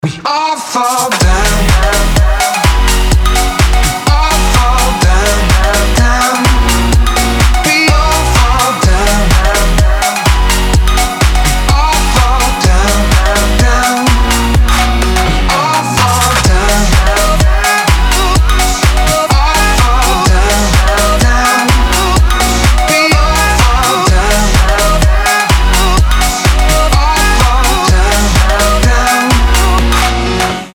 • Качество: 320, Stereo
dance
future house
club
Jackin House
Заводной и энергичный Jackin House